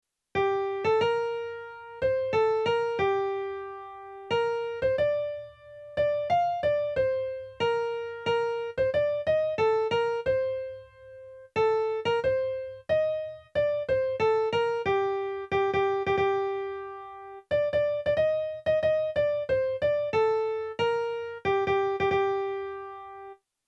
Ja queda poc per a què ens trobem per assajar, però encara us passo un fitxer, la segona veu de “Els segadors”.